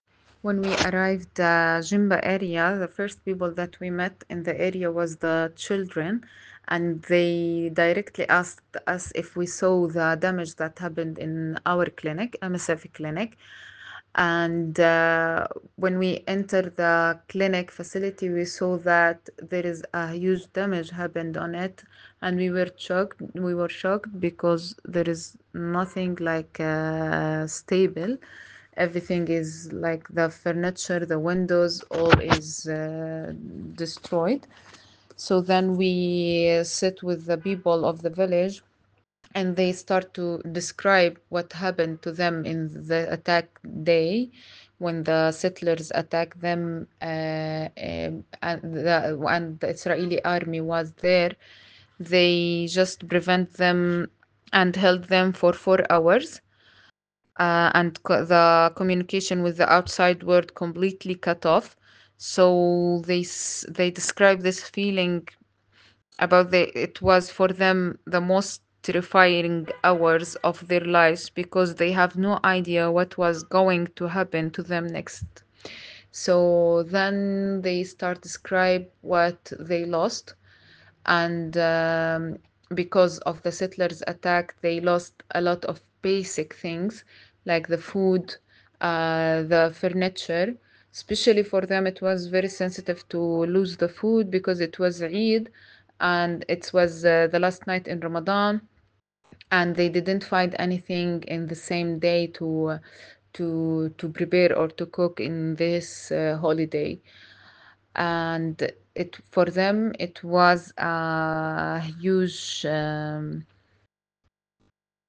Voice note